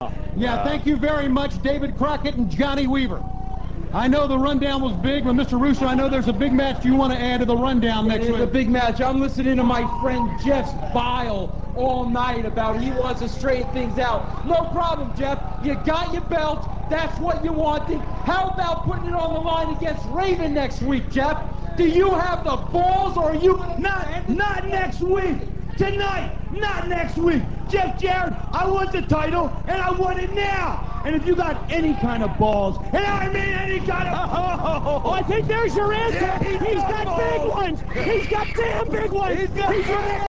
raven91.rm - This clip comes from NWA/TNA - [1.29.03]. Tony Schiavone interviews Vince Russo and Raven. Raven challenges Jarrett for the NWA Title, but Jarrett attacks Raven in the S.E.X locker room.